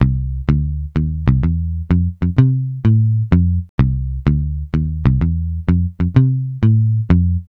Swingerz 2 Bass-C.wav